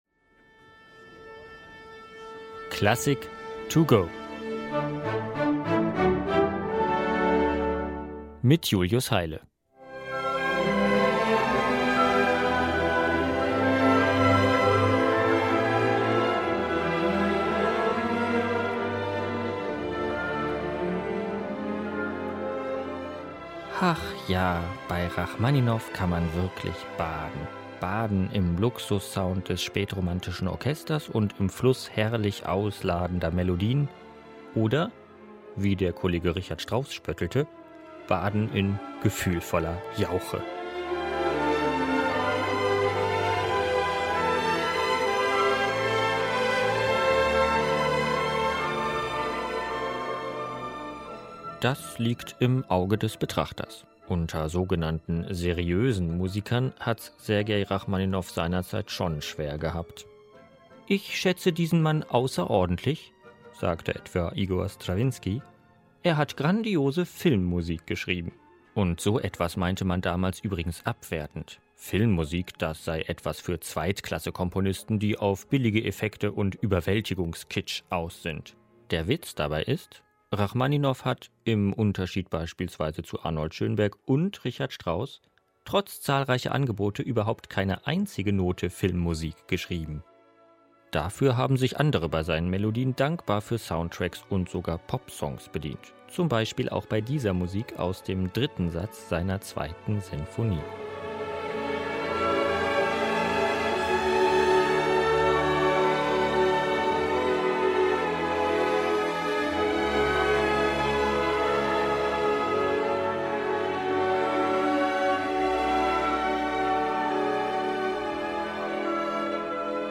"Klassik to Go" - die digitale Werkeinführung zum Download!